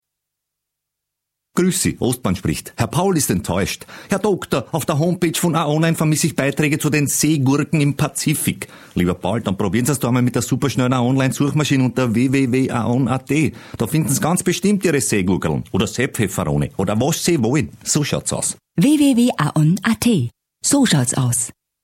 Hoerfunk_Aon_Seegurke.mp3